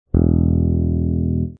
Cuerda cuarta del bajo: MI (E)
La cuarta cuerda del bajo es la más grave en un 4 cuerdas, y está afinada en MI, o E. Es una cuerda fundamental para empezar a tocar tus primeras líneas de bajo y acompañar a una banda desde el registro grave.
cuerda-mi-al-aire.mp3